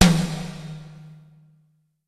Percusión 5: timbal 4
membranófono
timbal
percusión
electrónico
golpe
sintetizador